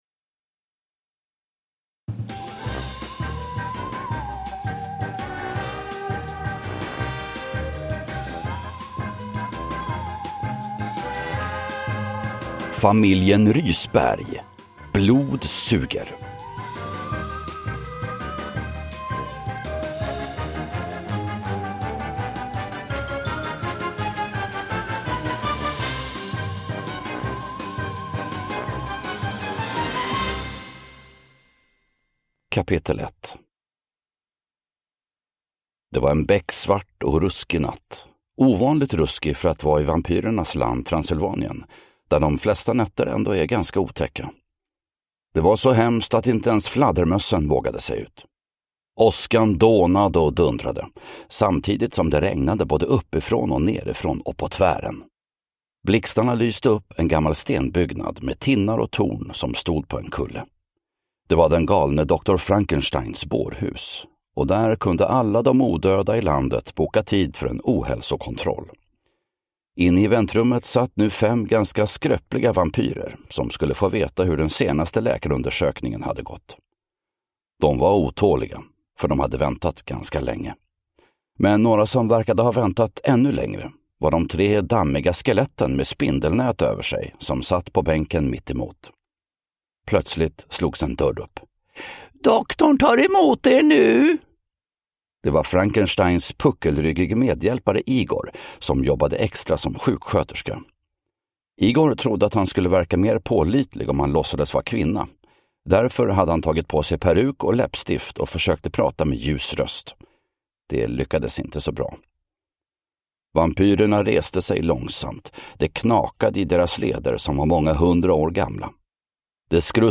Blod suger – Ljudbok